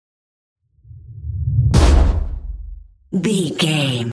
Dramatic hit deep scary trailer
Sound Effects
Atonal
heavy
intense
dark
aggressive
hits